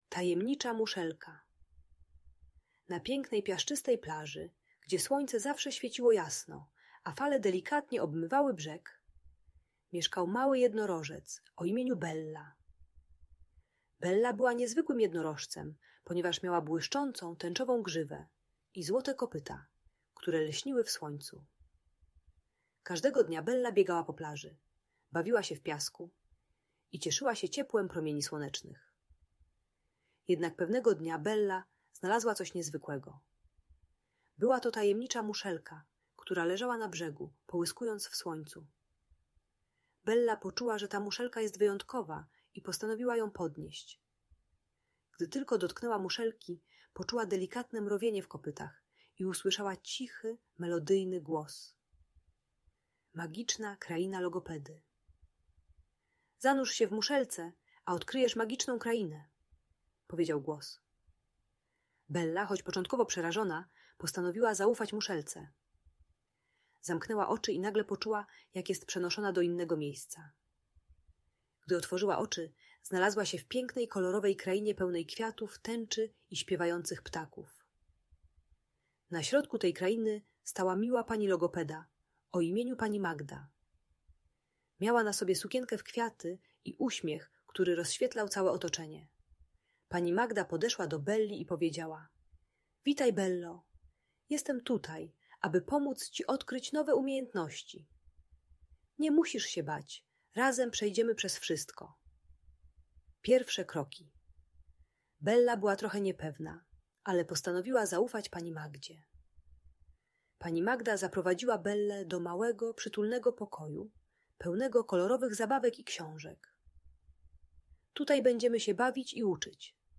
Tajemnicza Muszelka - Magiczna historia o odwadze i nauce - Audiobajka